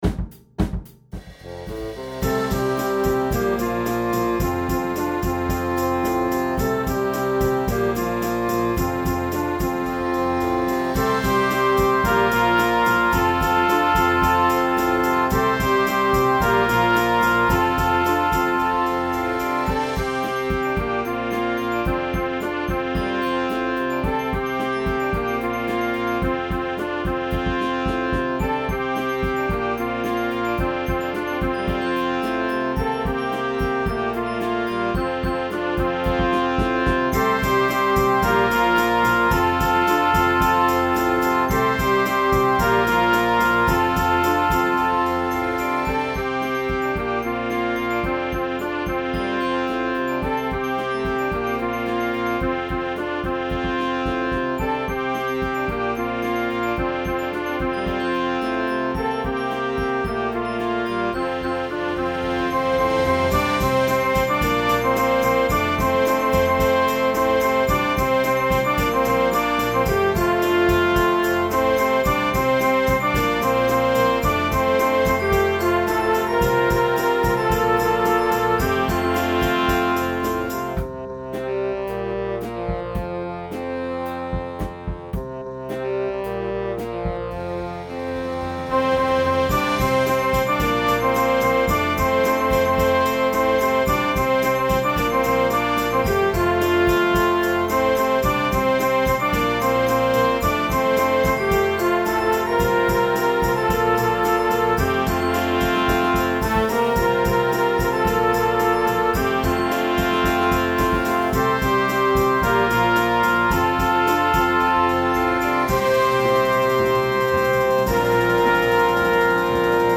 oldies, pop, country, rock, instructional, children